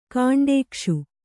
♪ kaṇḍēkṣu